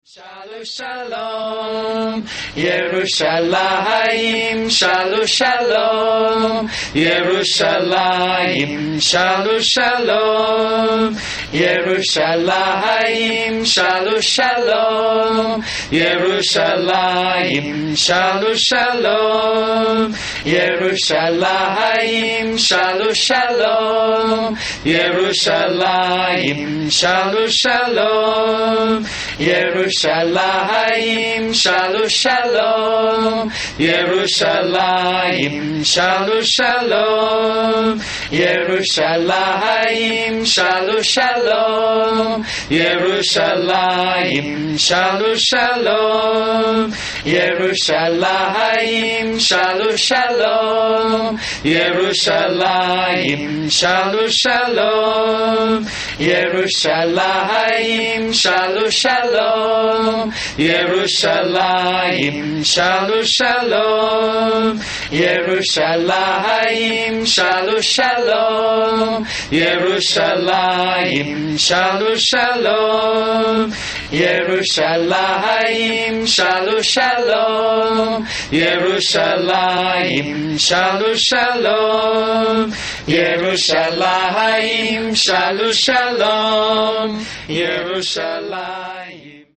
Sacred Hebrew Chant : : Pray for Peace in Jerusalem
*Recorded on the steps of Beit Shmuel - Mercaz Shimshon, Jerusalem,